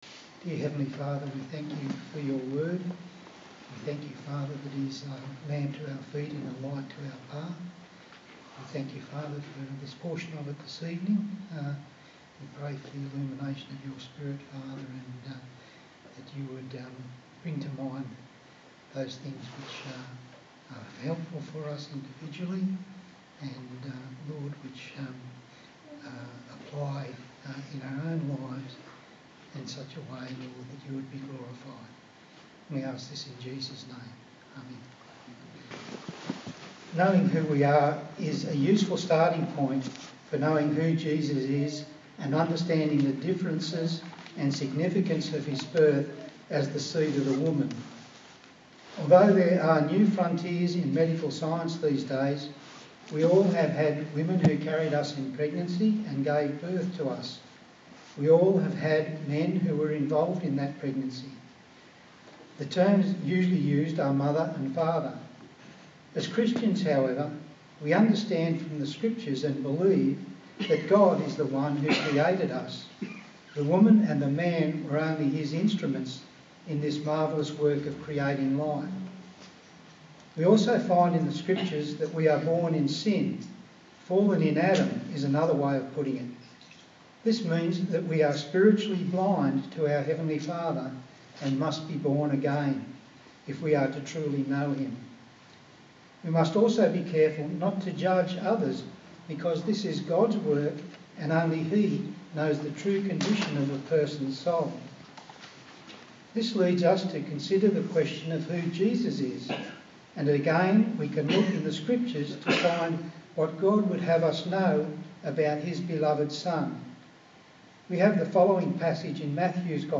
A sermon in the series on the book of Genesis